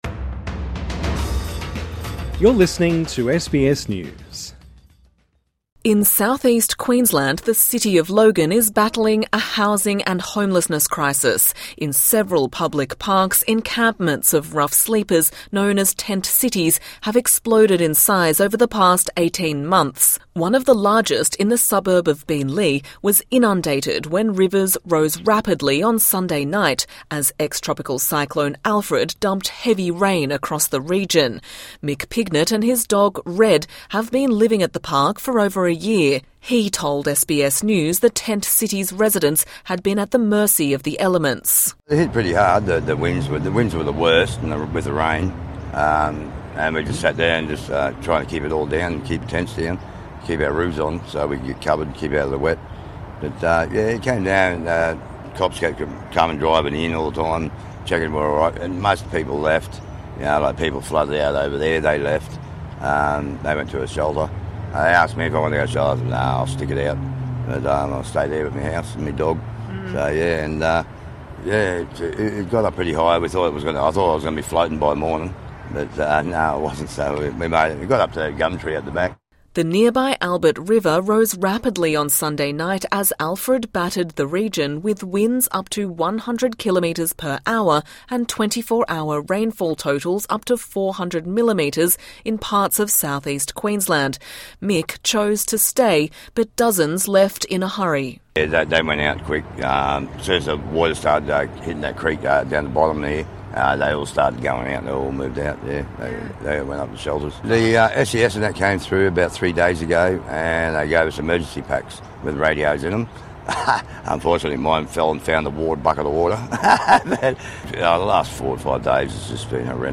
SBS has visited a tent city just south of Brisbane, which flooded after heavy rainfall, in an area struggling to cope with limited housing supply and rising cost of living.